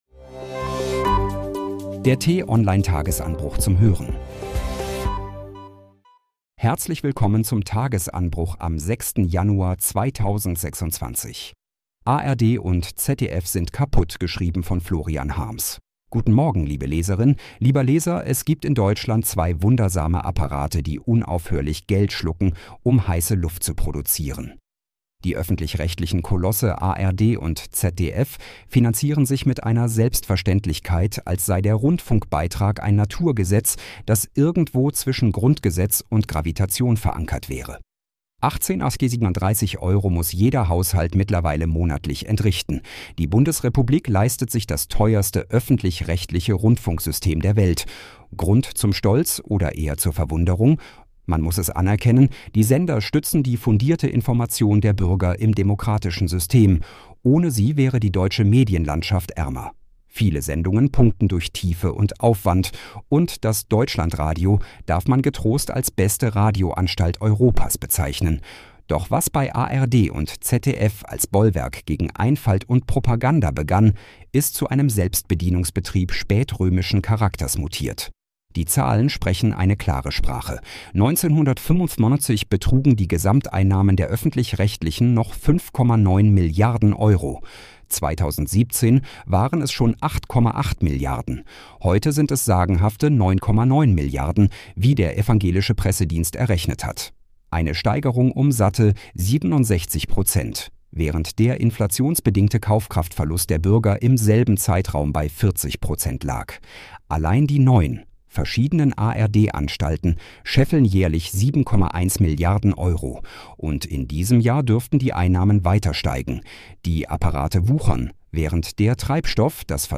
Den „Tagesanbruch“-Podcast gibt es immer montags bis freitags ab 6 Uhr zum Start in den Tag vorgelesen von einer freundlichen KI-Stimme – am Wochenende mit einer tiefgründigeren Diskussion.